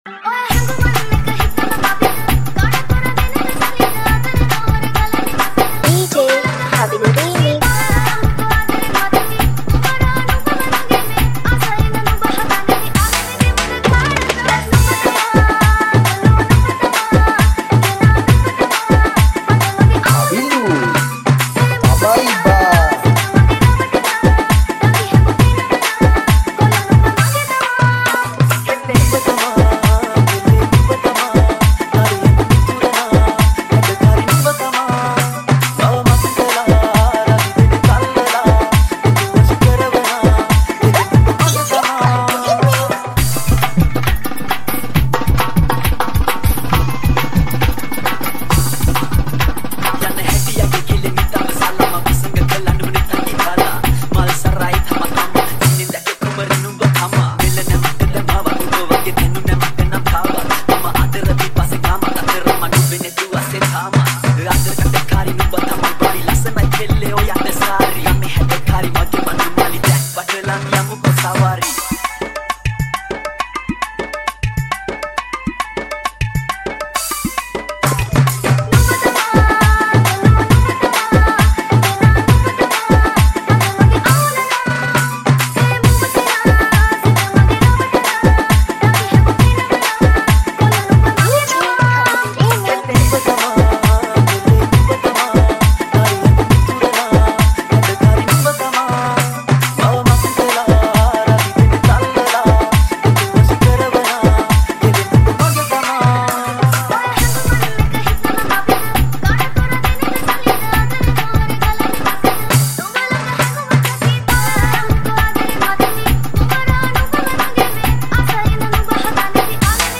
High quality Sri Lankan remix MP3 (2.6).